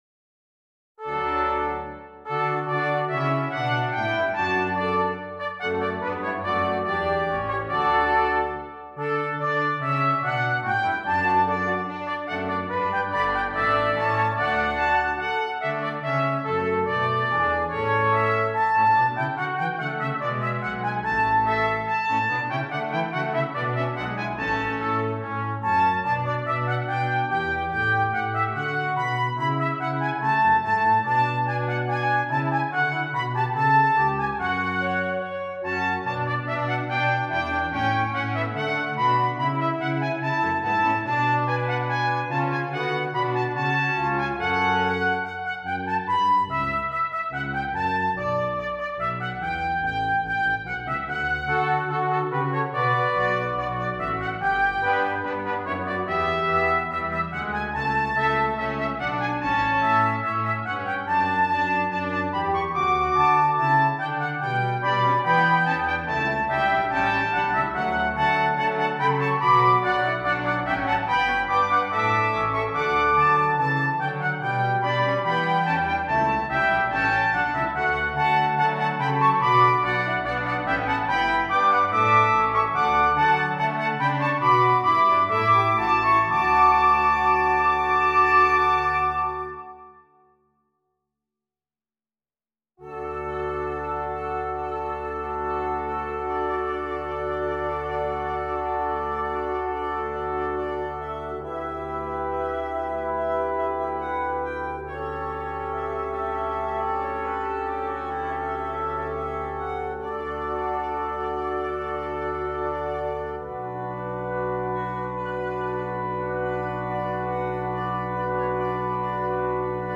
Brass Quintet and Soprano